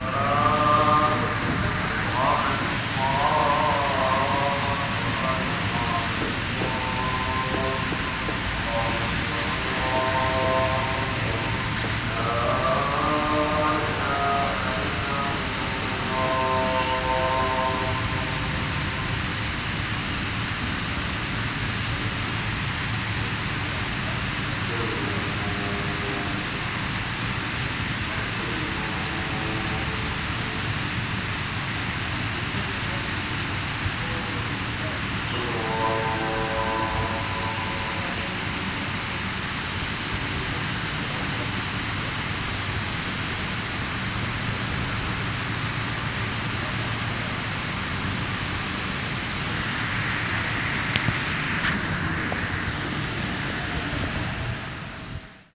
Riyadh Four Seasons - call to prayer and fountain
A fountain hisses outside the Four Seasons Hotel, beneath Riyadh's Kingdom Tower, as a brief call to prayer is broadcast outside from a nearby minaret. Recorded on Samsung Galaxy 7.